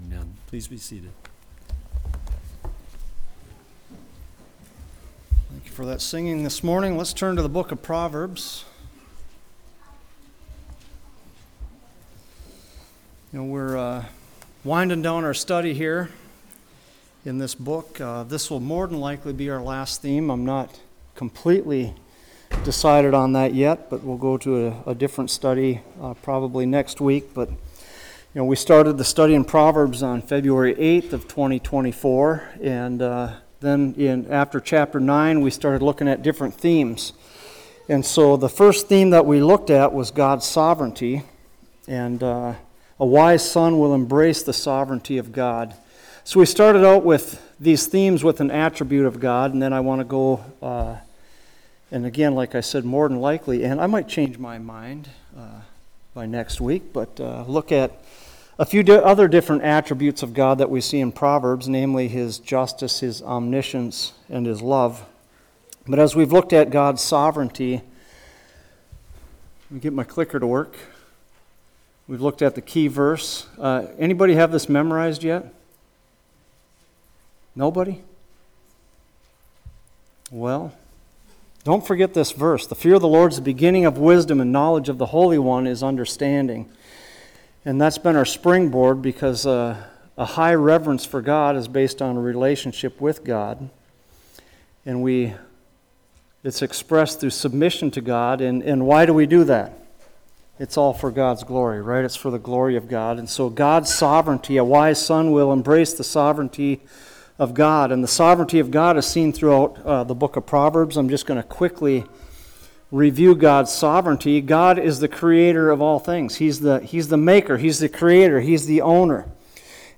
Sermons | Deer River Bible Church